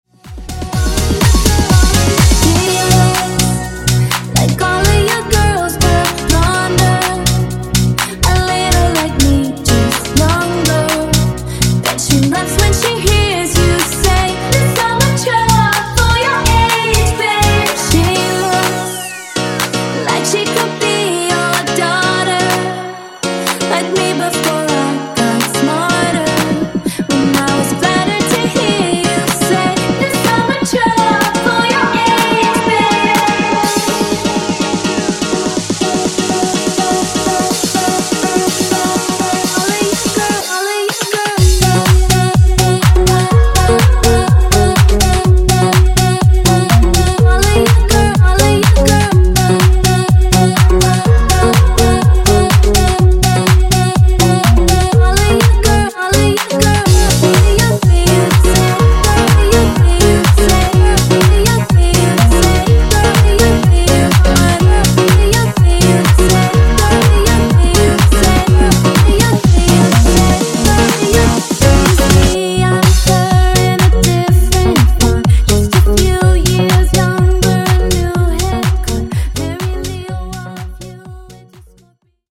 DANCE , RE-DRUM , TOP40
124bpm